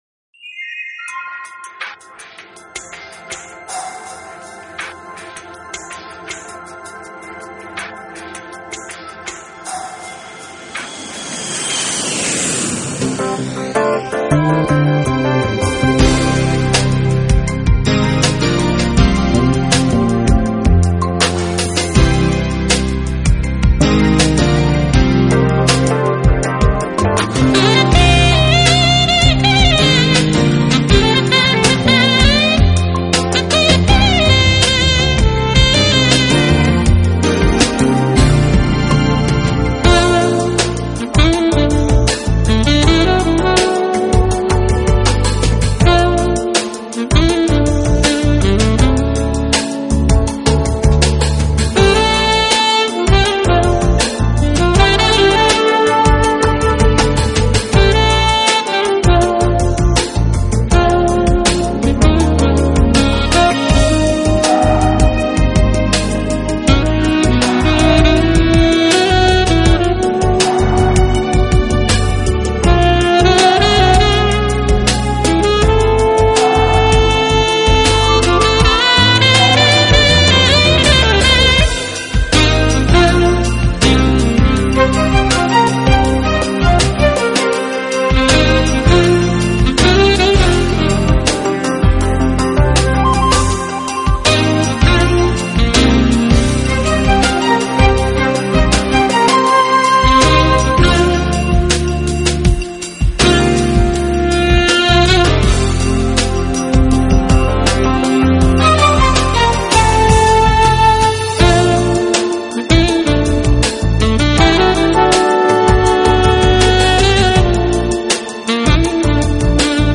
爵士萨克斯